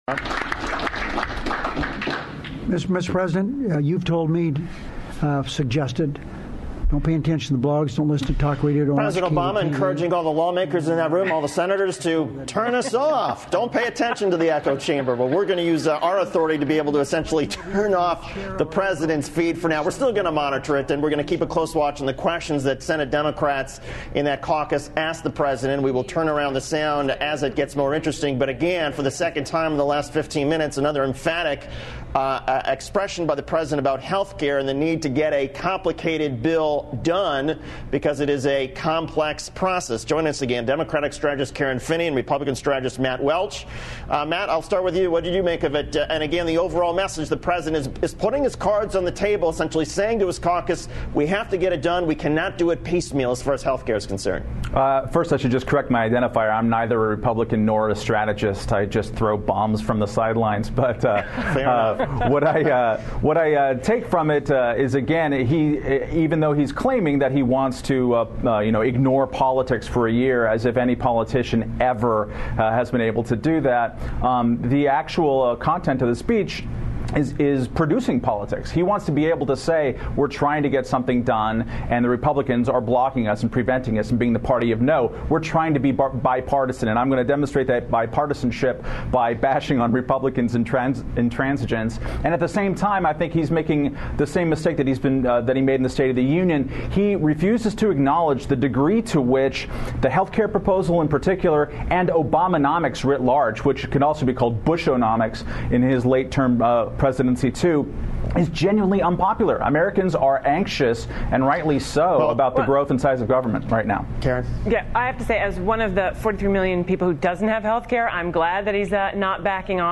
On February 3, Reason's Matt Welch appeared on MSNBC to discuss President Obama's flagging support among the American people. Thrill to Matt being misidentified as a "Republican strategist" and be inspired when he points out that Obama's policies, many of which simply continue awful programs initiated by George W. Bush, are genuinely unpopular.